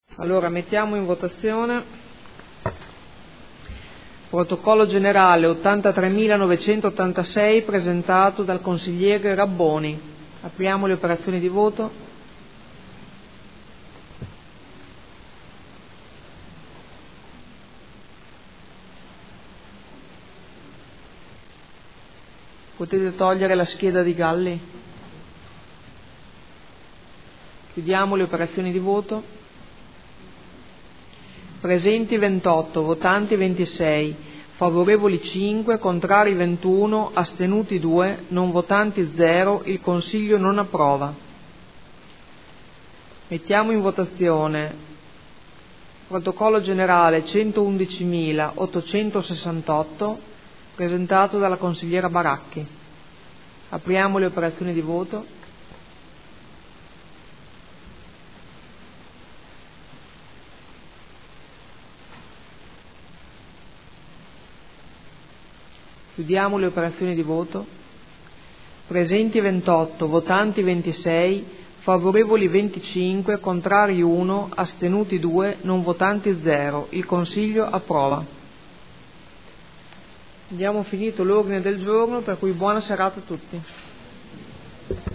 Seduta del 16 ottobre. Votazione su ordine del Giorno n°83986 e n°111868